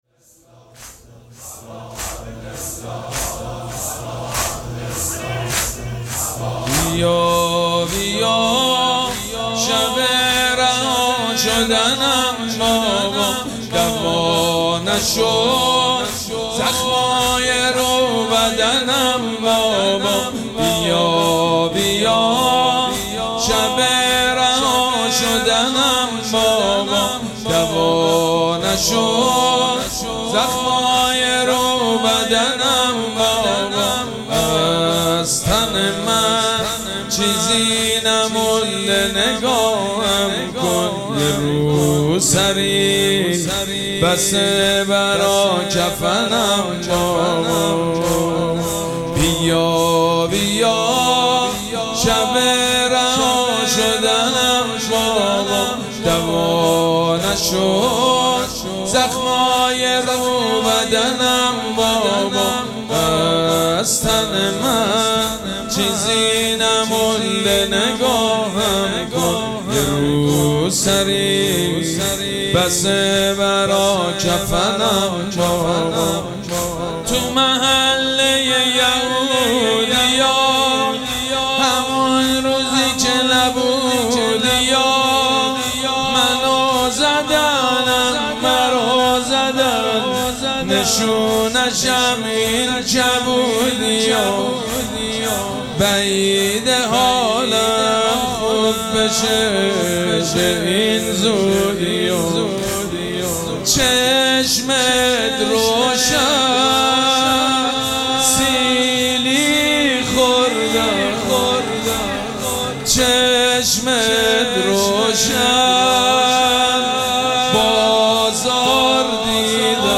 مراسم عزاداری شام شهادت حضرت رقیه سلام الله علیها
حاج سید مجید بنی فاطمه